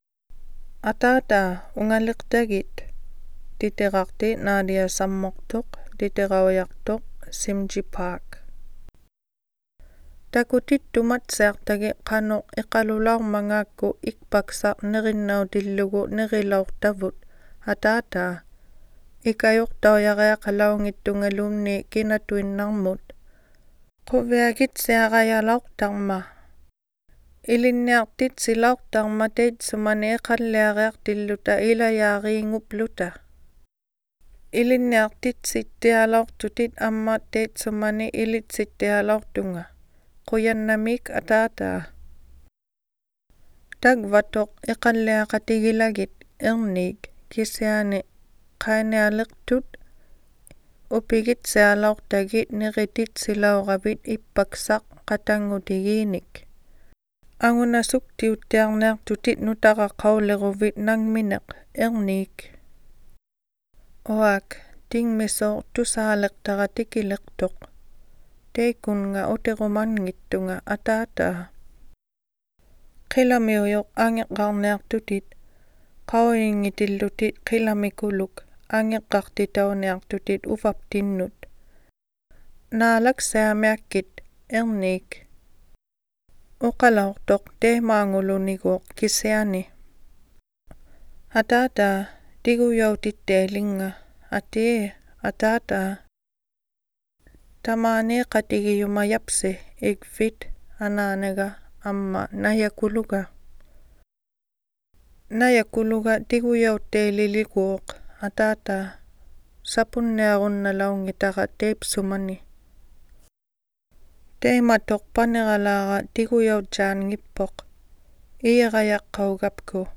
Dad, I Miss You (Inuktitut Audiobook)
Dad-I-Miss-You-Audiobook-Inuktitut.mp3